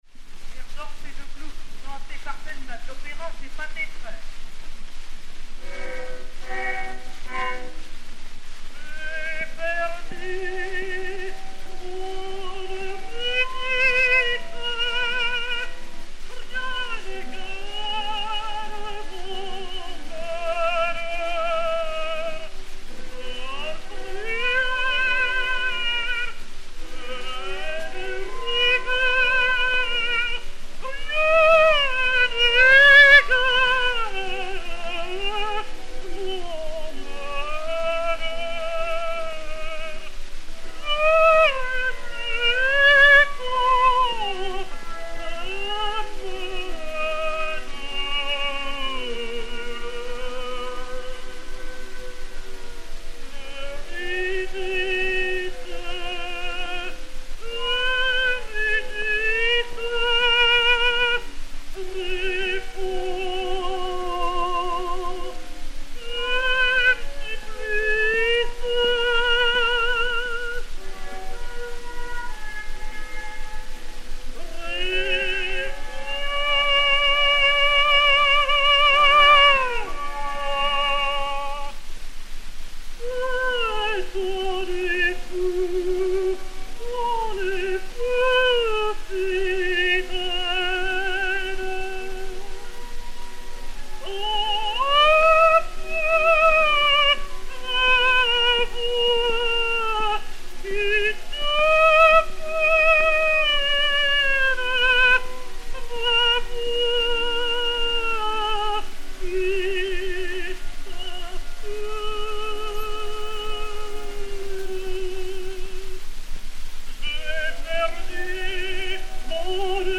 Marie Delna (Orphée) et Orchestre
Pathé saphir 90 tours n° 4878, enr. en 1907